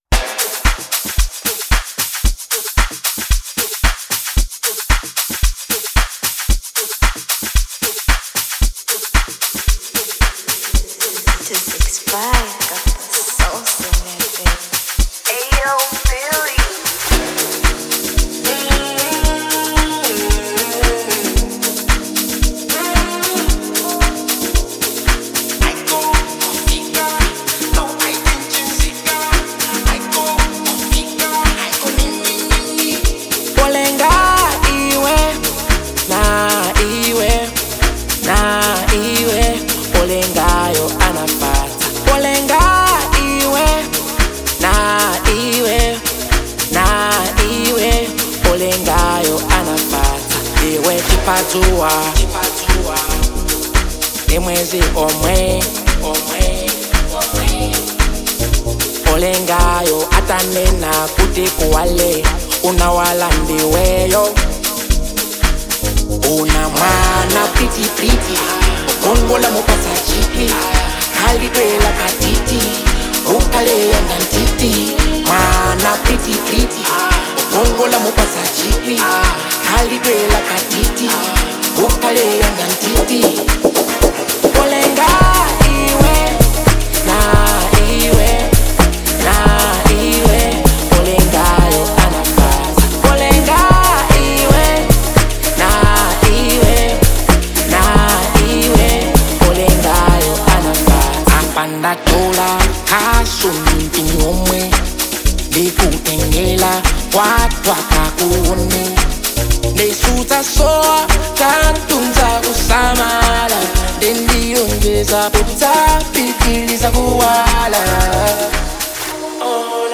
Genre : Amapiano
Blending soulful production with meaningful storytelling